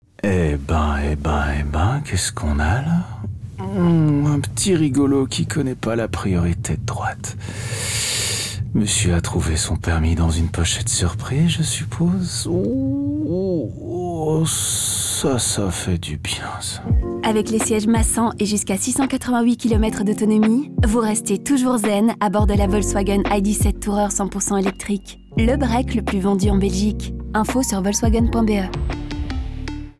C'est exactement ce qui se passe dans ces spots publicitaires : ils nous parlent de cette rage au volant, mais en sortant des sentiers battus. Certes, les conducteurs expriment crûment leur colère envers les autres automobilistes... mais ils le font d'un ton étonnamment calme, presque béat.
Le contraste ludique entre les propos peu amènes et la manière détendue dont ils sont prononcés montre parfaitement comment l'ID.7 transforme même les situations les plus tendues en un pur moment de zenitude.